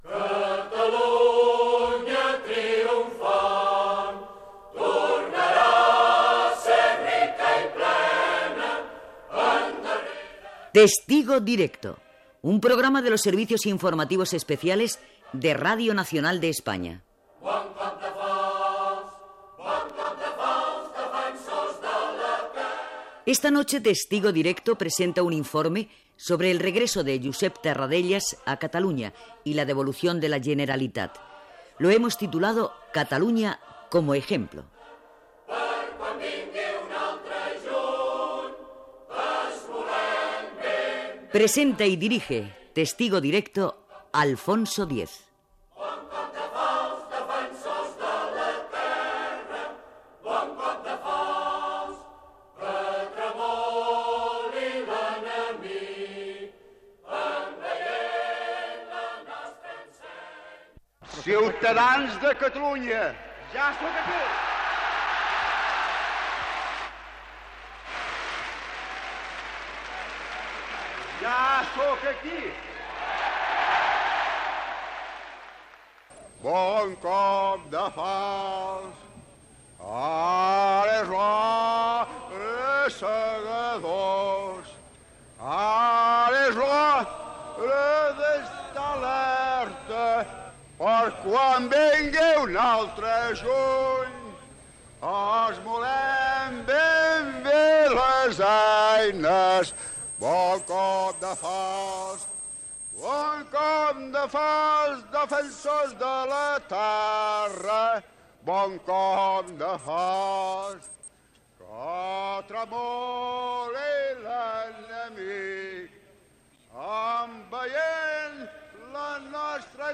Josep Tarradellas canta l'himne "Els Segadors", perfil polític
Informatiu